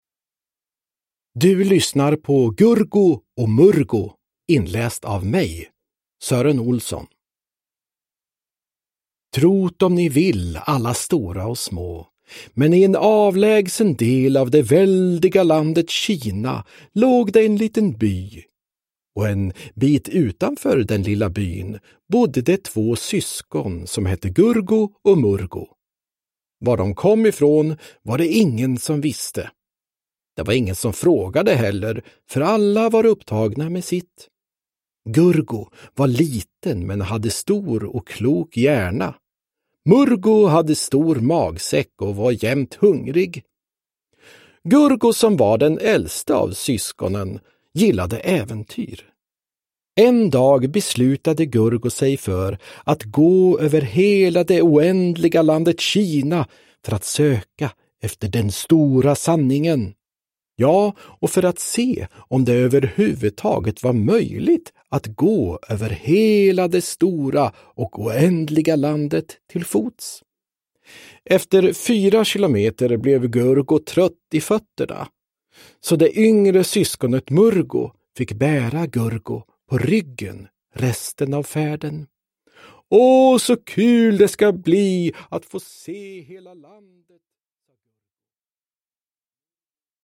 Gurgo och Murgo – Ljudbok – Laddas ner
Uppläsare: Sören Olsson, Anders Jacobsson